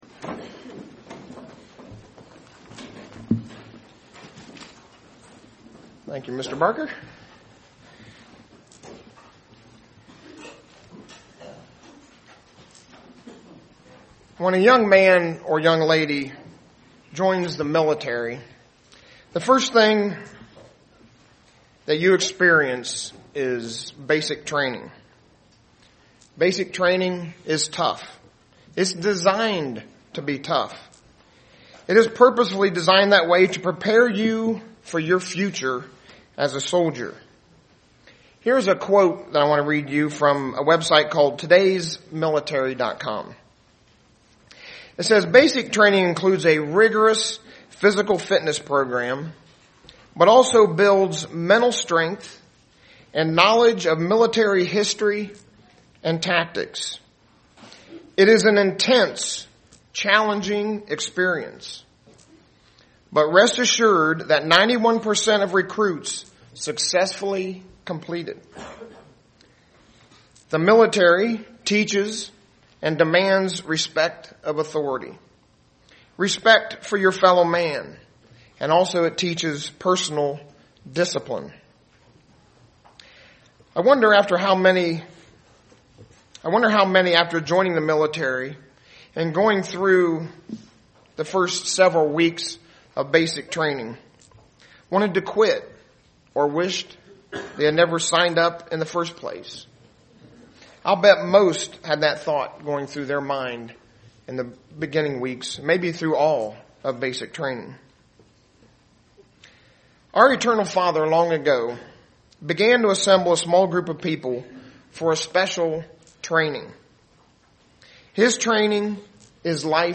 Given in Dayton, OH
Print A visit of the lessons learned at camp UCG Sermon Studying the bible?